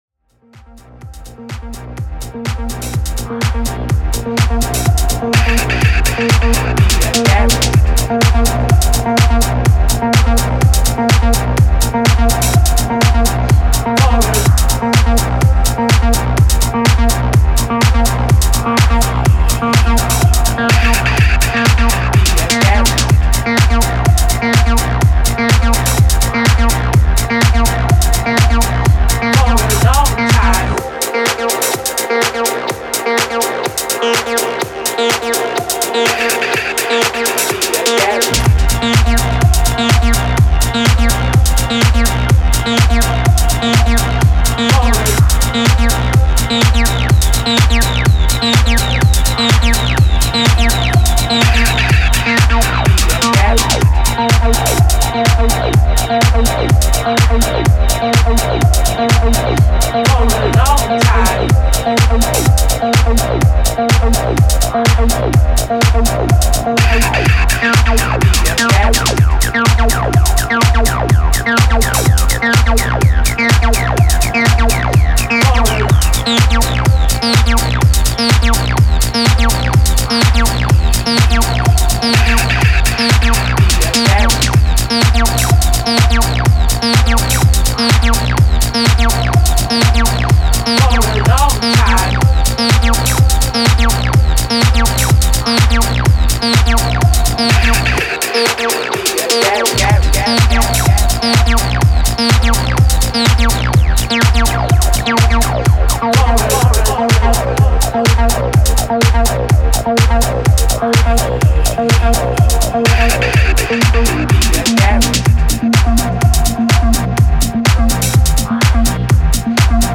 I’m a fan of the T-8’s Bass synth - sync’d it up to my OT and came up with a couple of loopy things :slight_smile: